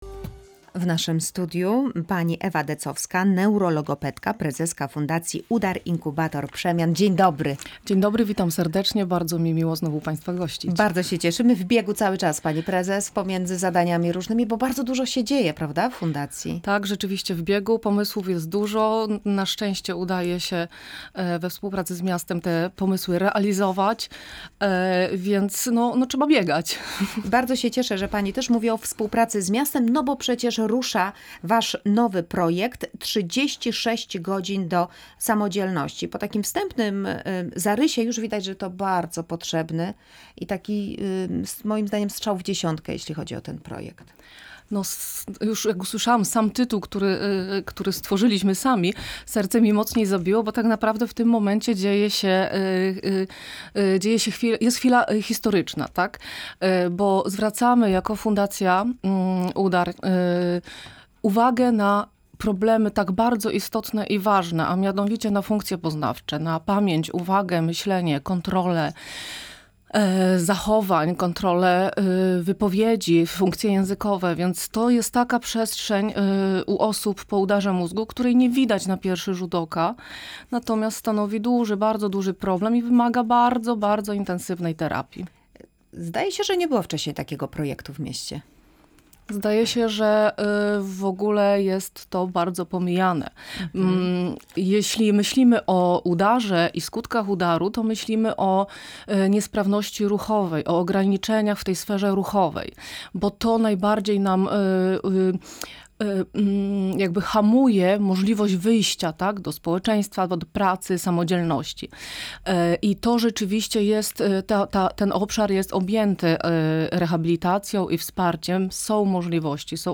Fundacja UDAR Inkubator Przemian • LIVE • Polskie Radio Rzeszów